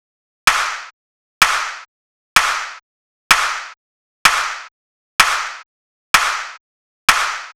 Clap Loop Ghetto.wav